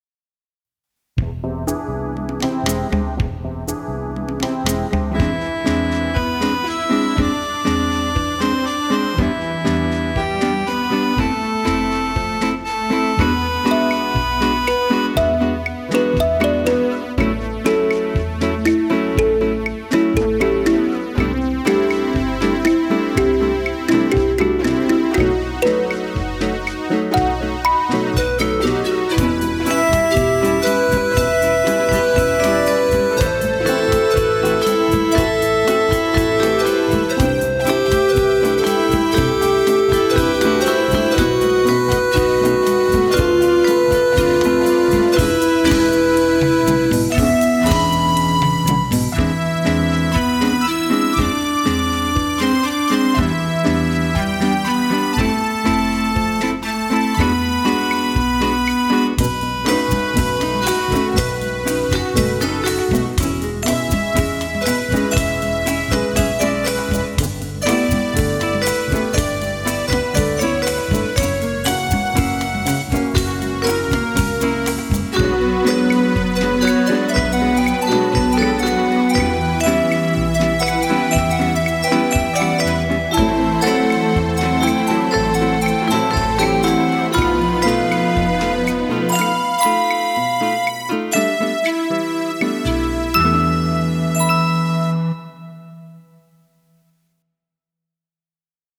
restored legacy synthesizer